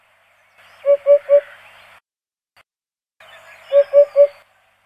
Huppe fasciée
Upupa epops
"Hup, hup, hup" fait-elle alors du haut d'un arbre pour marquer son territoire.
huppe.mp3